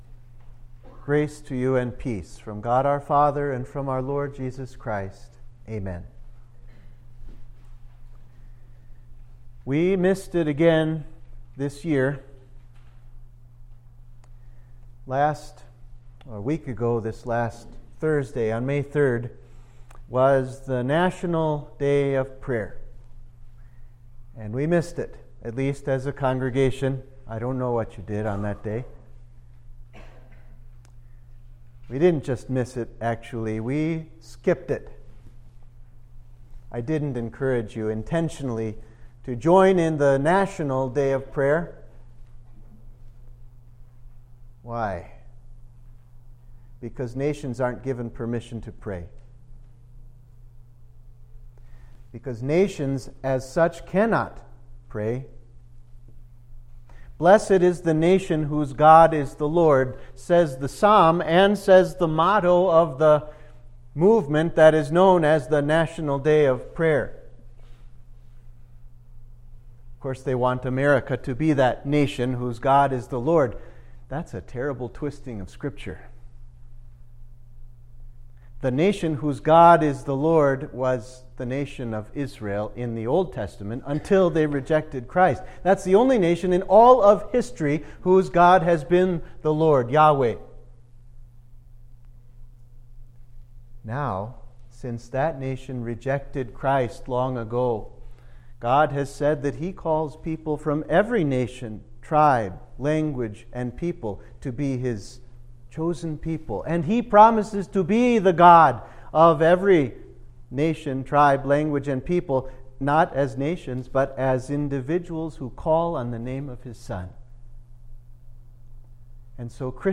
Sermon for Rogate – Sixth Sunday of Easter